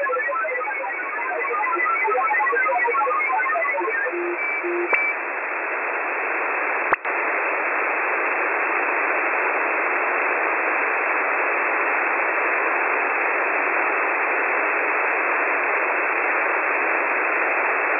File:Websdr recording start 2021-01-09T18 48 09Z 7050.2kHz.wav - Signal Identification Wiki